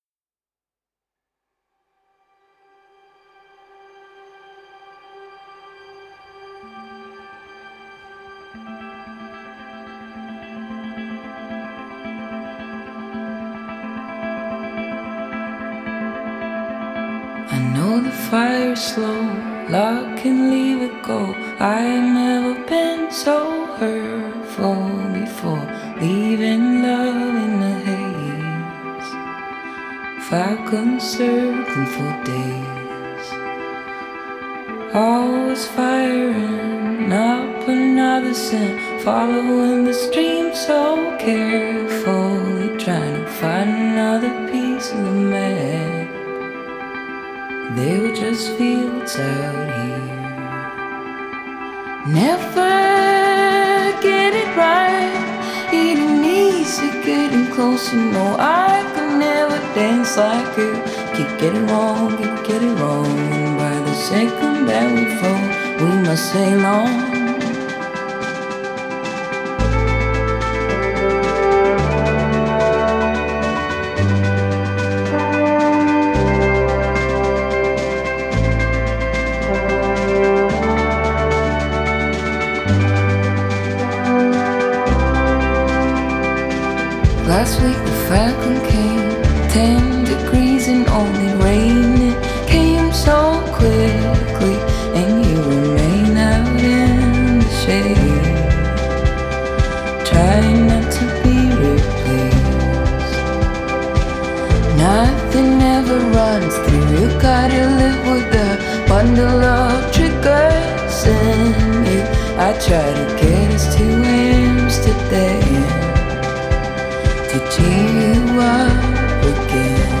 Genre: Pop Folk